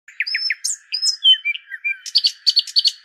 disengage.wav